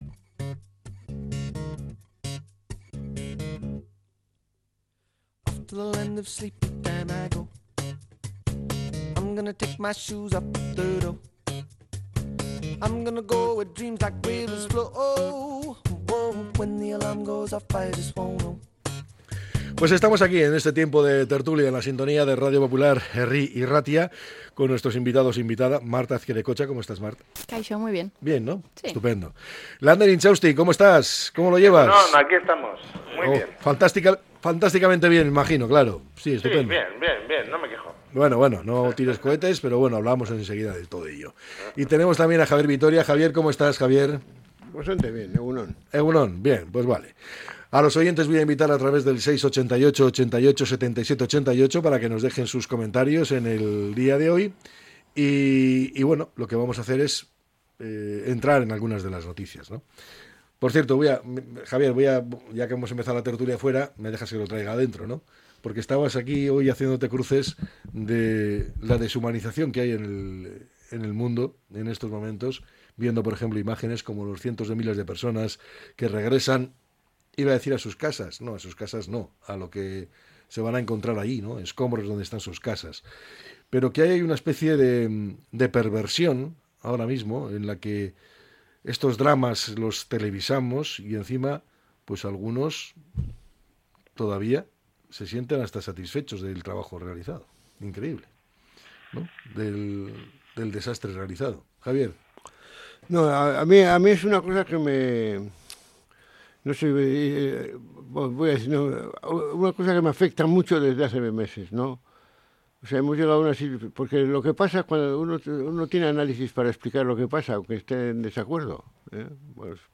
La tertulia 28-01-25.